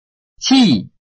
臺灣客語拼音學習網-客語聽讀拼-饒平腔-單韻母
拼音查詢：【饒平腔】ci ~請點選不同聲調拼音聽聽看!(例字漢字部分屬參考性質)